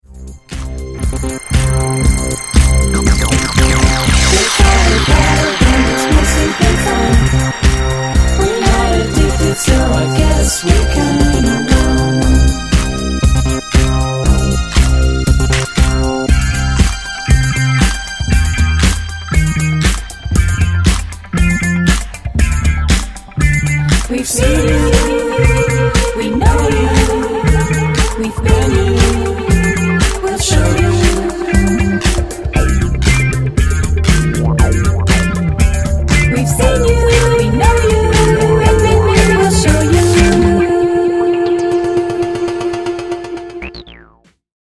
psychedelic funk